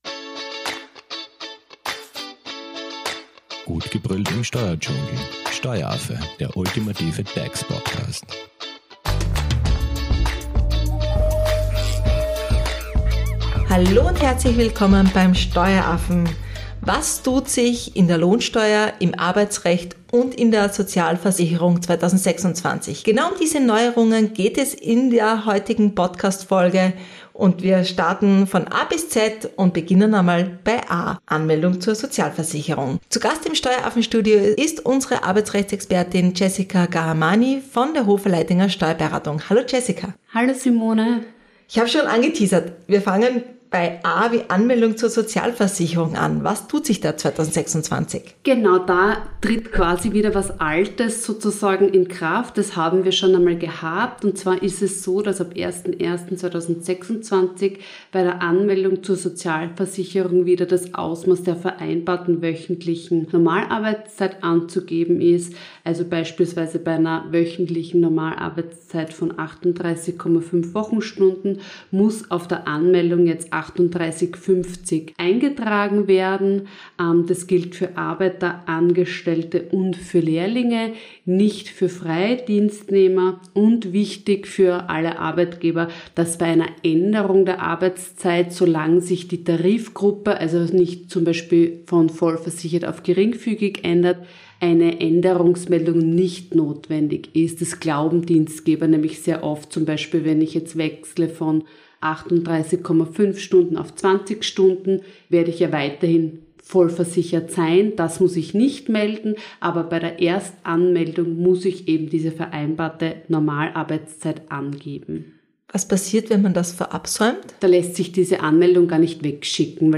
In Teil 1 unserer Reihe fokussieren wir uns auf Updates von A bis K und zeigen dir, welche Auswirkungen sie auf deinen Berufsalltag haben können. Zu Gast im Steueraffen-Studio